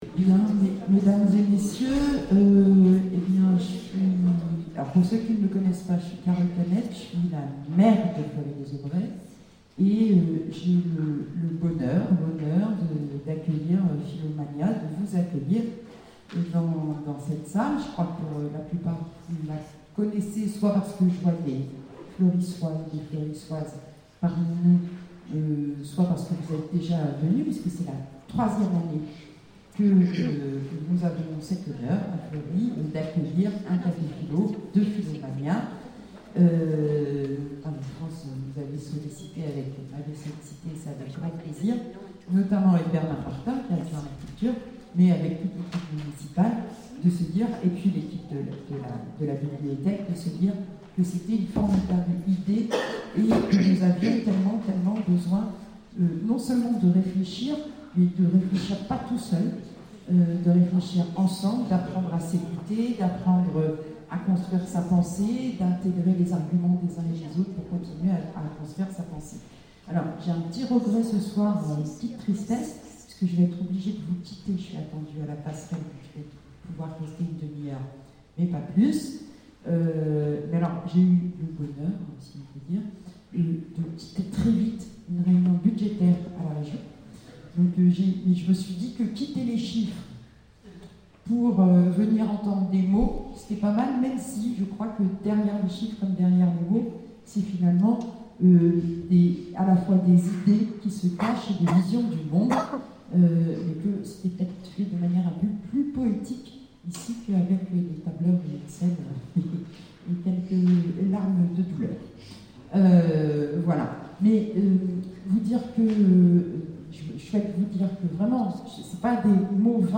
Conférences et cafés-philo, Orléans
CAFÉ-PHILO PHILOMANIA Comment se positionner entre réalité et vérité ?